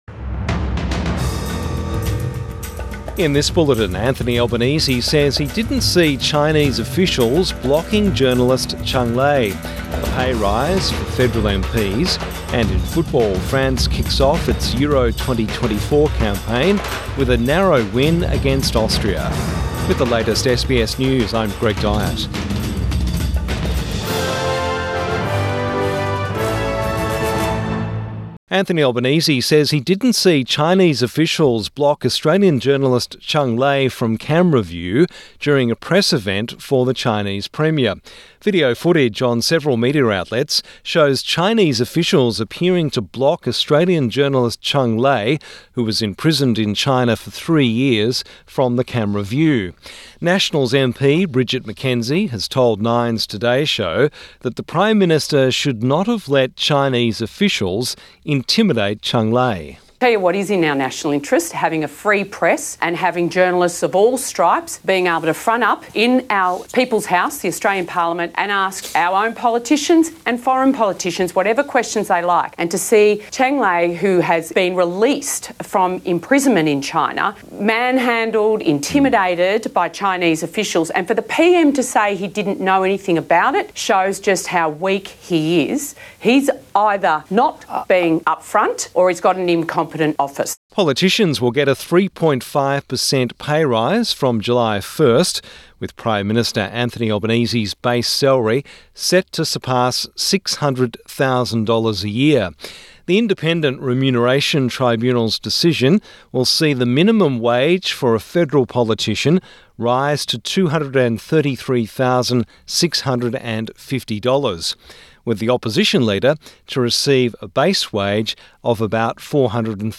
Midday News Bulletin 18 June 2024